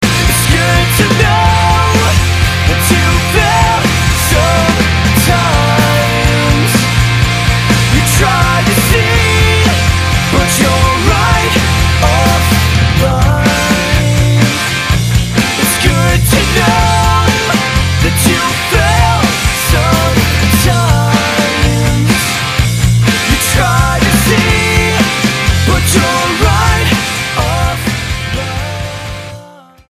STYLE: Rock
this is thoughtful rock
excellent light and shade